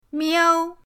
miao1.mp3